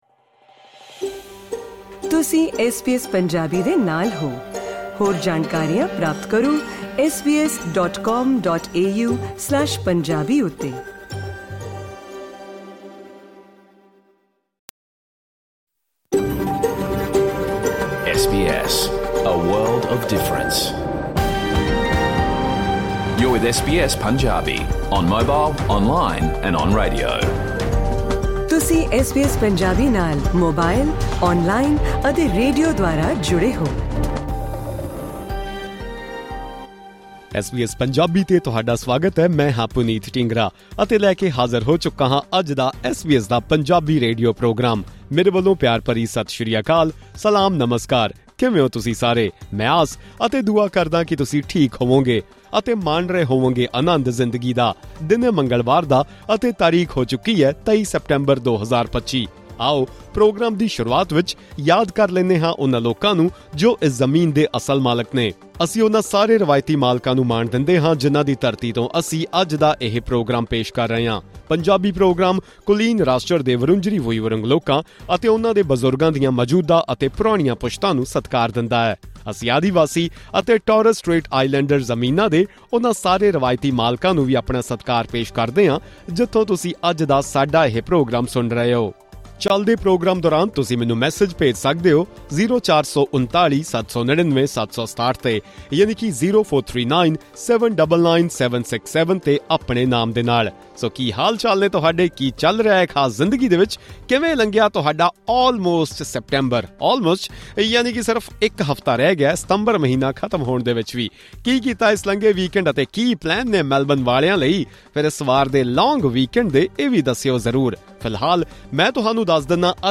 In this radio program of SBS Punjabi, you will be able to listen to Australia’s national anthem in Punjabi and also understand the meaning of its lyrics. In addition, the show features Australian and international news, as well as updates from both East and West Punjab.